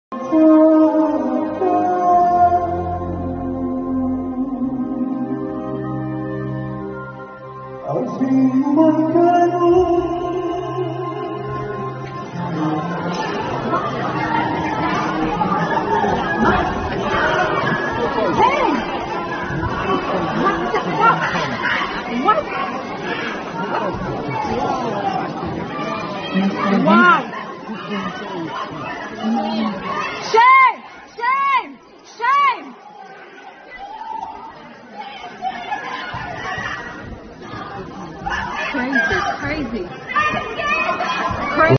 מפגינה פרו- פלסטינית התיזה צבע אדום על דוד דאור בהופעתו בוורשה